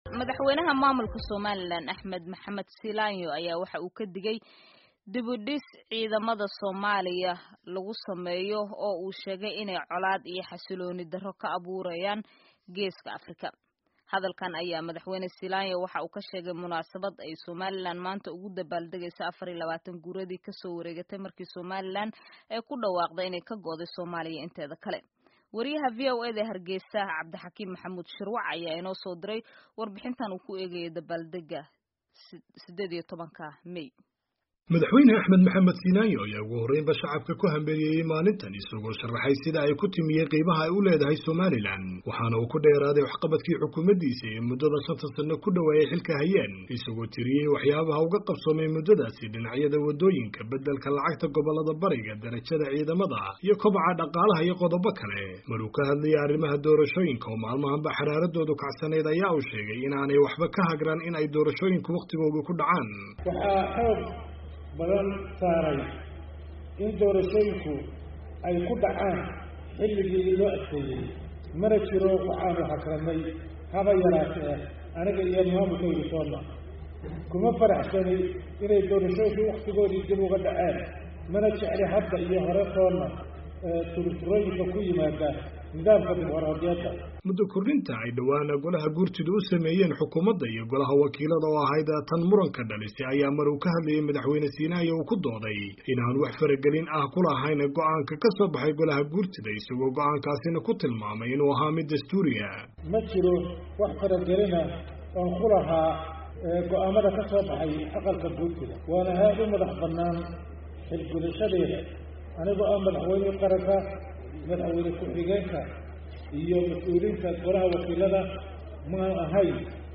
Madaxwayne Axmed Maxamed Siilaanyo oo maanta ka hadlayay xuska 18-ka May ee Somaliland, wuxuu ka digay dib u dhiska ciidamada dowladda Soomaaliya oo uu sheegay inay xasillooni darro ka abuurayaan gobolka.